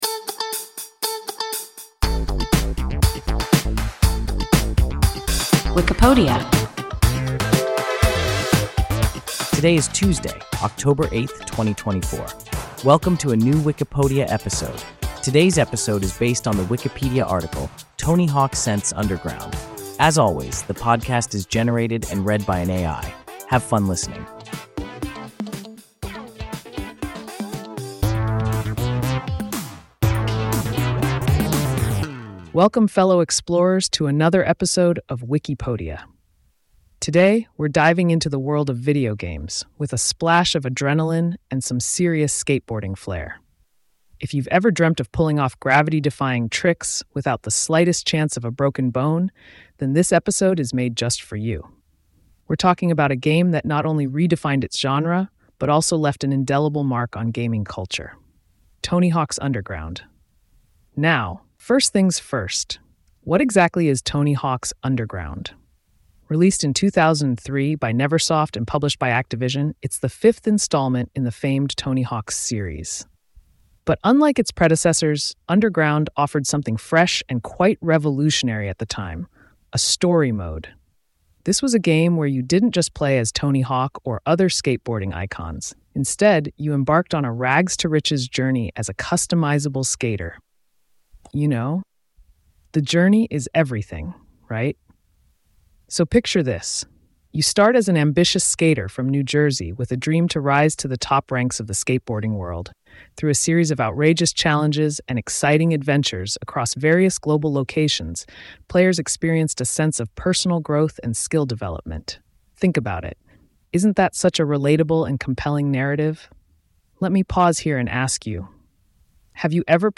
Wikipodia – an AI podcast